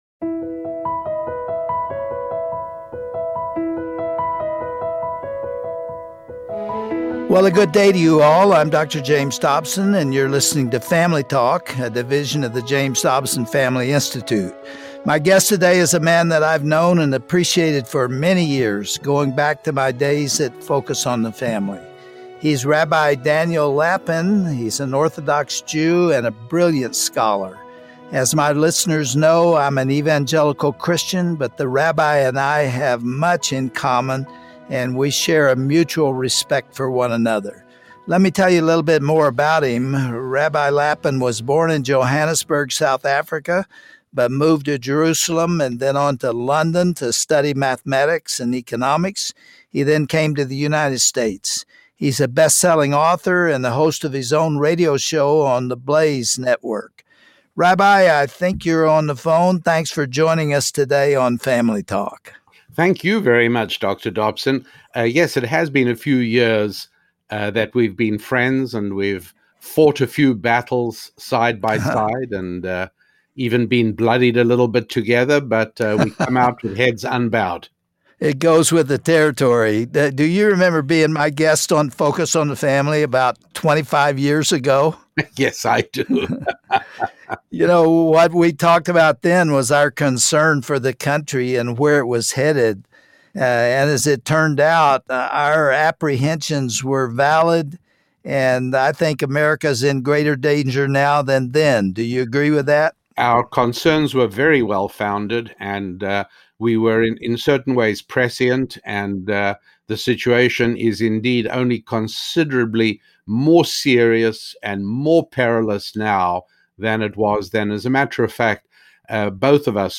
Dr. James Dobson and Rabbi Daniel Lapin discuss Judaism's and Christianity's shared view of biblical morality, and how secular society has destroyed God's conceptions of marriage, gender identity, and government.